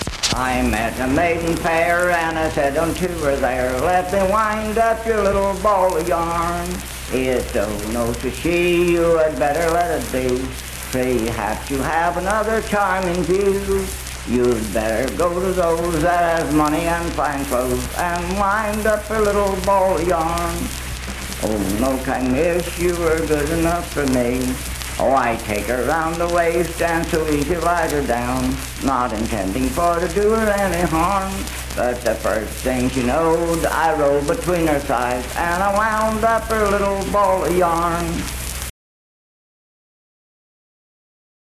Unaccompanied vocal music performance
Bawdy Songs
Voice (sung)
Roane County (W. Va.), Spencer (W. Va.)